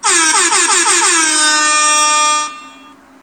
horn.ogg